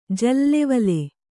♪ jallevale